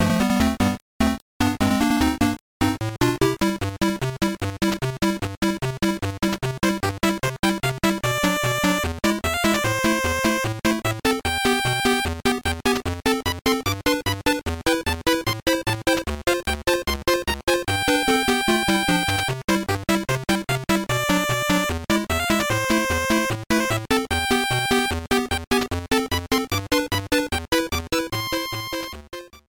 Trimmed to 30 seconds and applied fade-out when needed